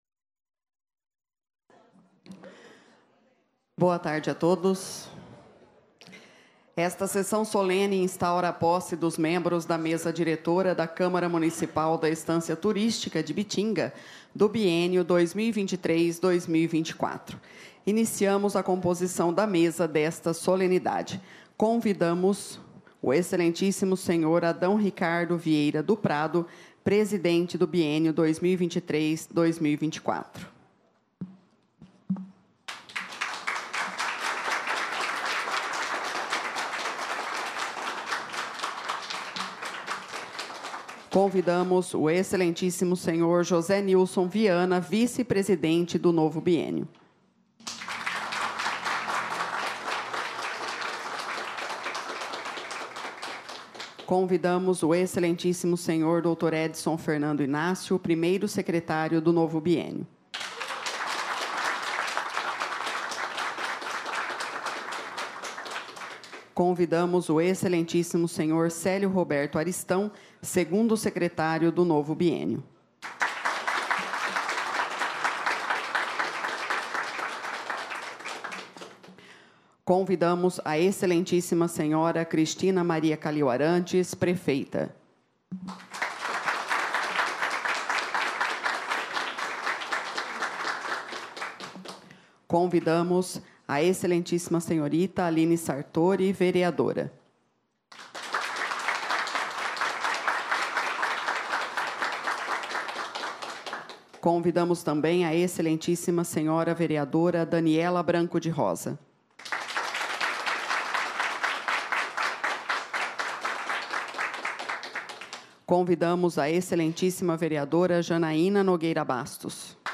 SESSÃO SOLENE DE POSSE DA MESA DIRETORA DA CÂMARA MUNICIPAL DA ESTÂNCIA TURÍSTICA DE IBITINGA – BIÊNIO 2023/2024